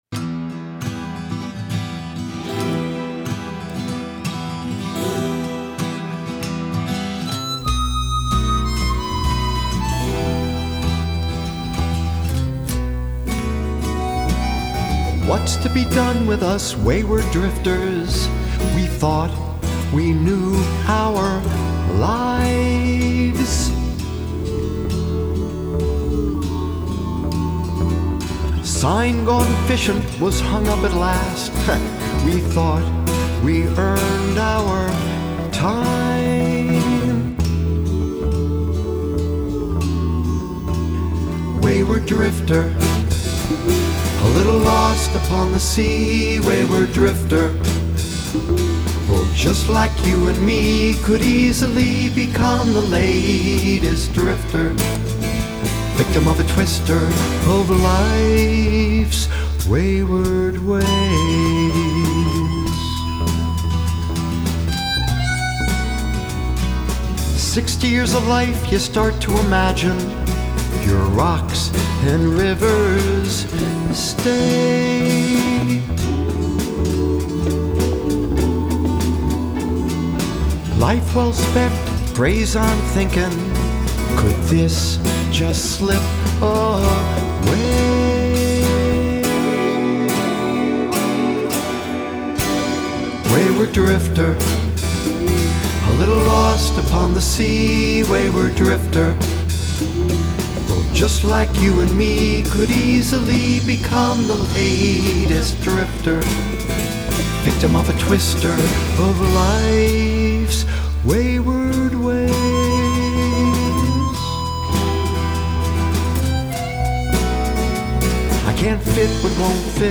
REMASTERED SONGS